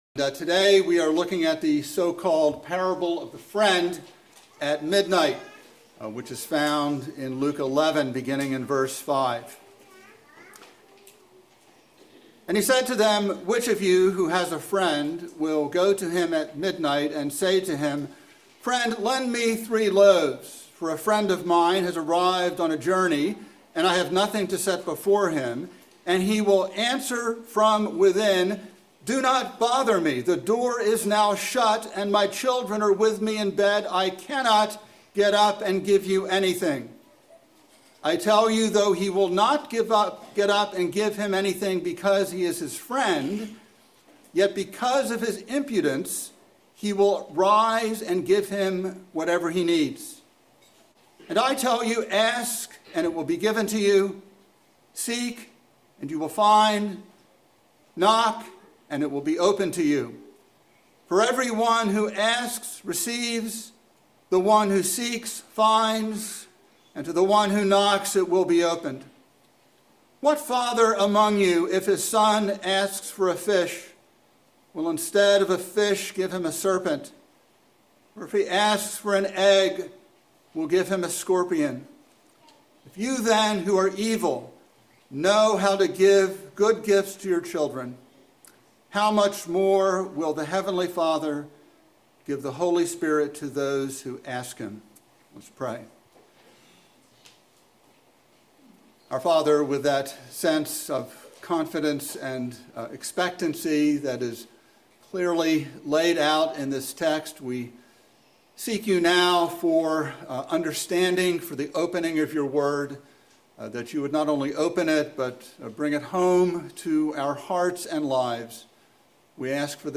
by Trinity Presbyterian Church | Apr 13, 2024 | Sermon